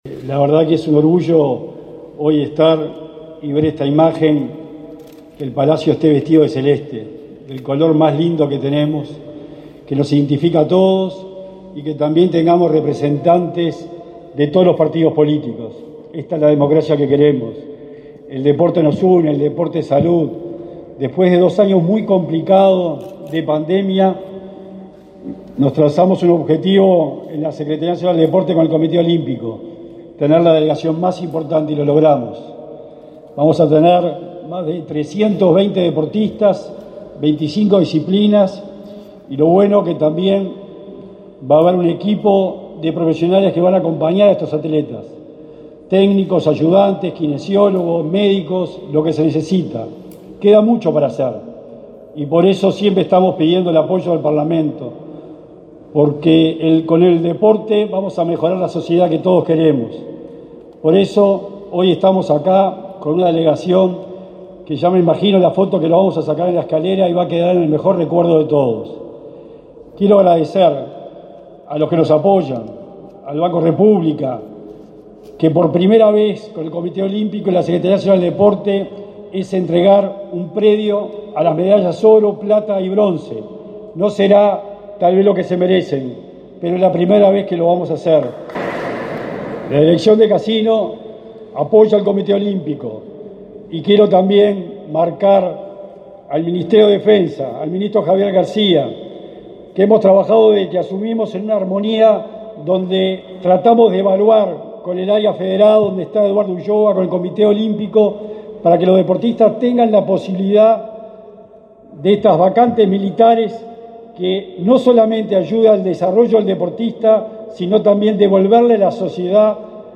Palabras de autoridades en despedida de atletas
Palabras de autoridades en despedida de atletas 28/07/2022 Compartir Facebook X Copiar enlace WhatsApp LinkedIn Este martes 27 en el Palacio Legislativo, el secretario del Deporte, Sebastián Bauzá, y la vicepresidenta de la República, Beatriz Argimón, participaron en el acto de despedida de los más de 300 atletas que competirán en los Juegos Odesur, que se realizarán en Paraguay.